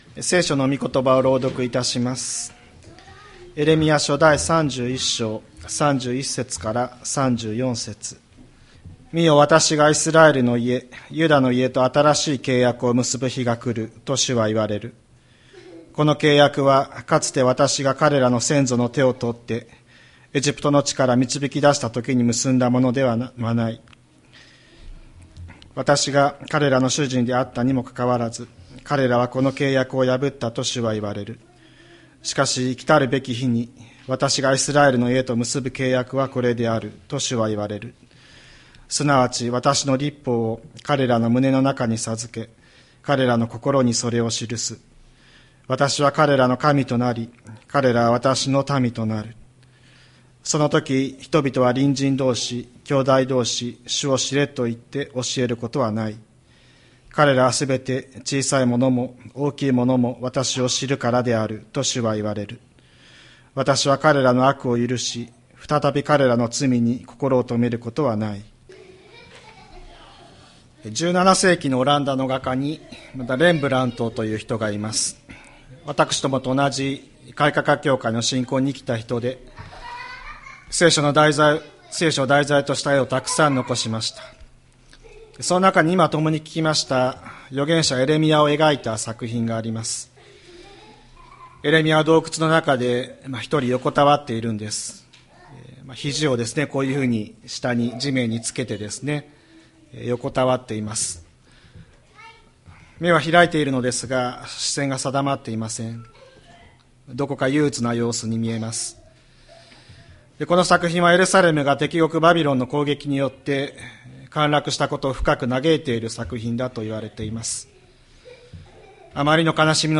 千里山教会 2025年03月02日の礼拝メッセージ。